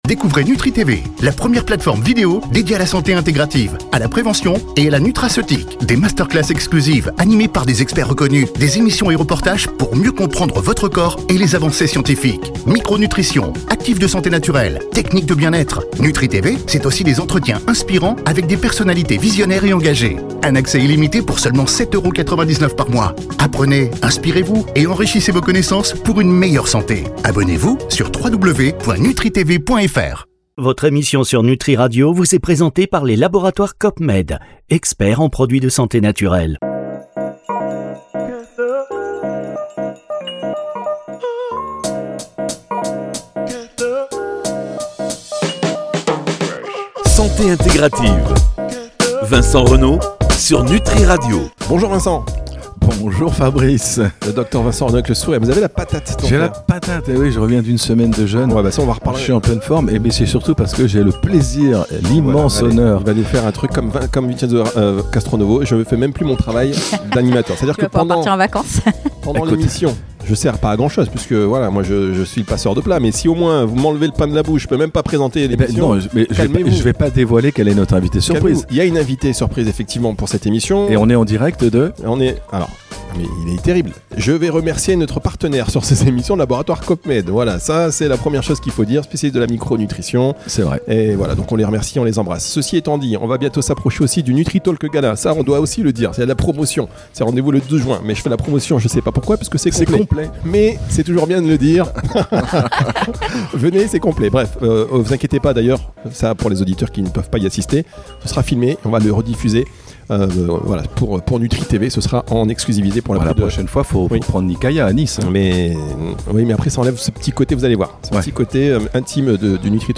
Une discussion pleine de pédagogie, de bon sens et de solutions concrètes, à écouter absolument.